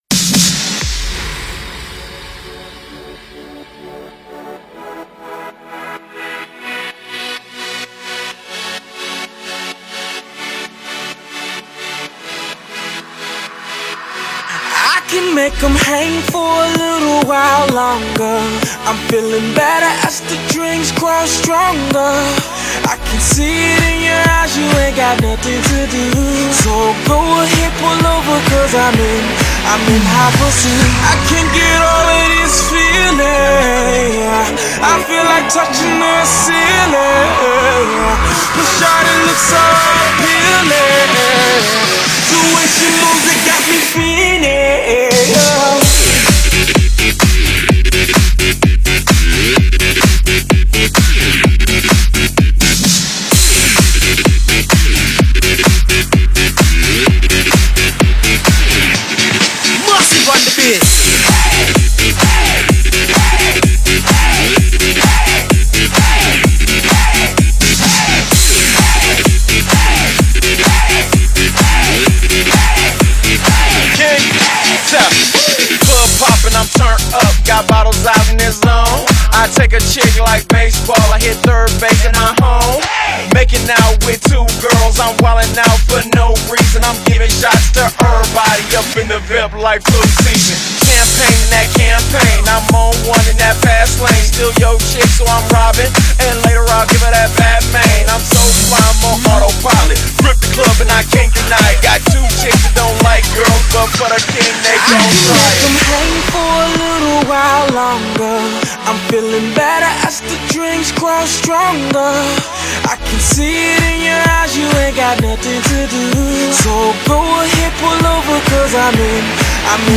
栏目：英文舞曲